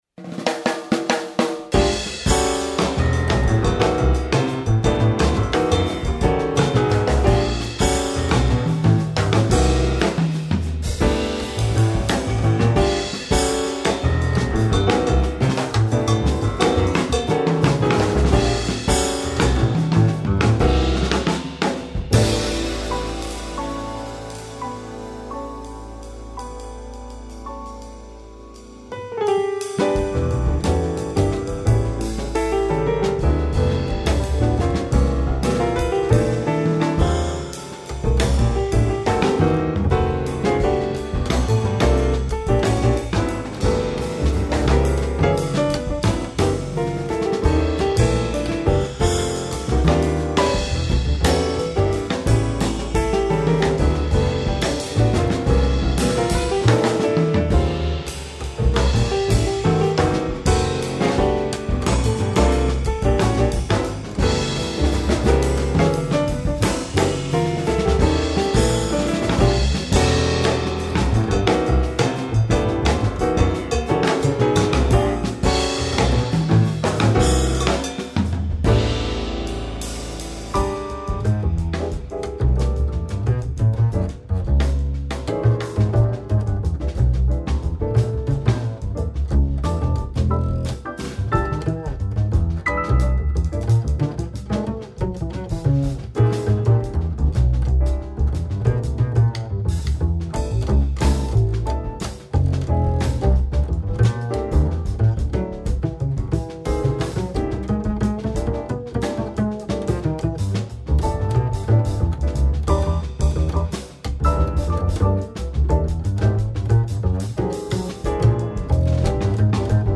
arco double-bass